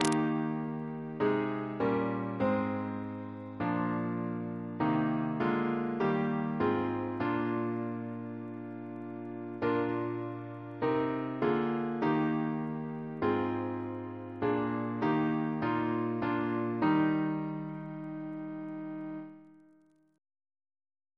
Double chant in E♭ Composer: Stephen Elvey (1805-1860), Organist of New College, Oxford; George's brother Reference psalters: ACP: 238